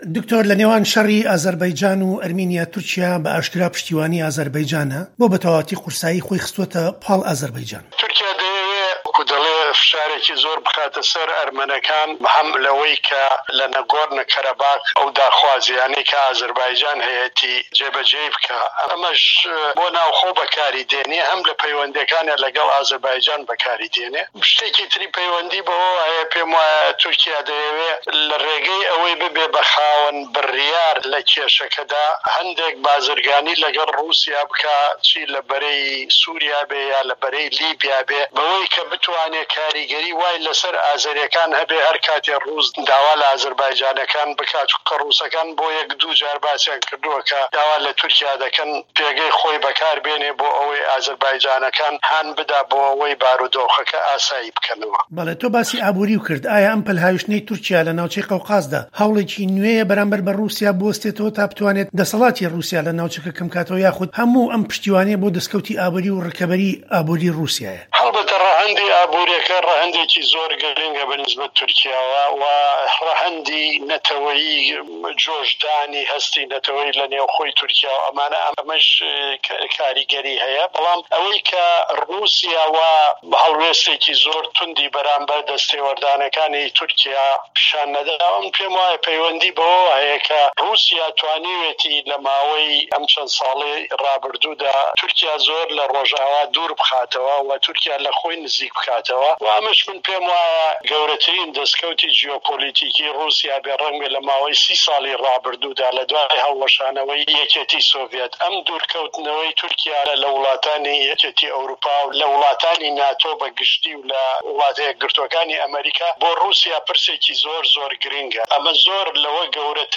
زانایری زیاتر لە درێژەی ووتوێژەکەدایە.